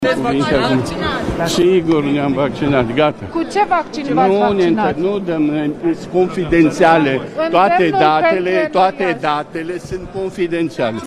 El a facut anunțul după ce a participat la ședința solemnă din Parlament, destinată sărbătoririi în avans a Zilei Naționale a României.
„Ne-am vaccinat. Toate datele sunt confidențiale”, a spus Patriarhul Daniel atunci când a fost întrebat cu cer s-a vaccinat anti-COVID.